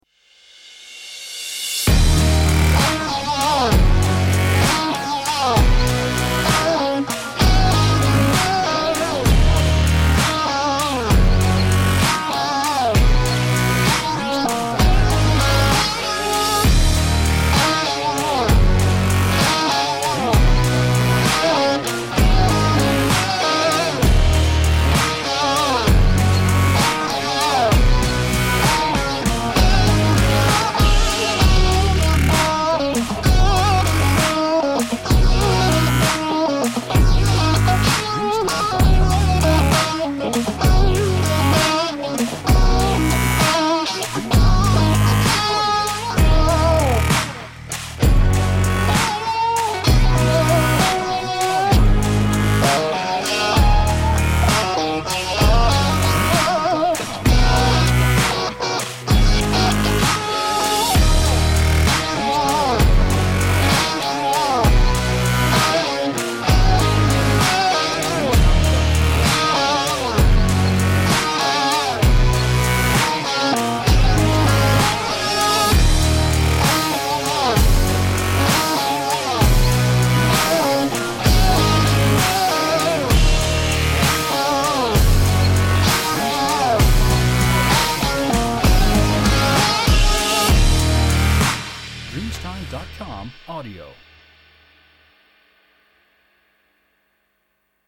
Fashion Indie Opener